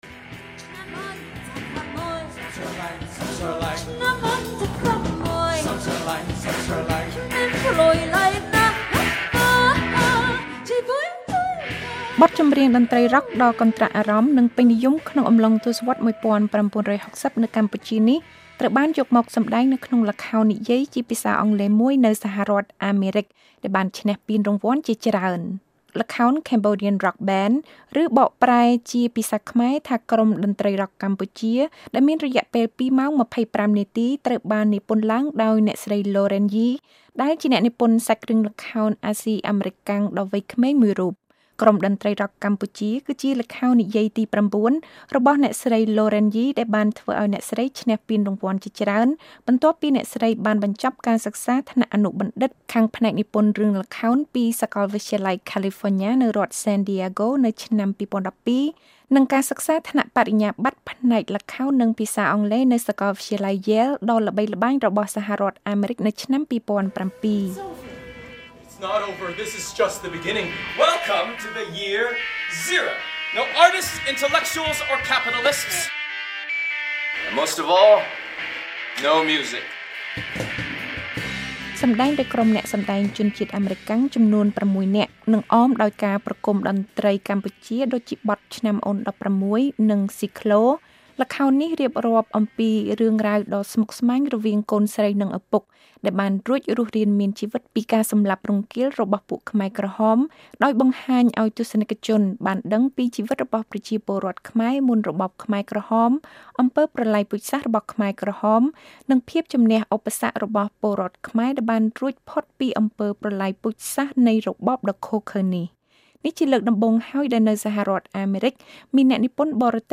សេចក្តីរាយការណ៍ជាសំឡេងអំពីល្ខោននិយាយ "Cambodian Rock Band" ឬ «ក្រុមភ្លេងសម័យខ្មែរ»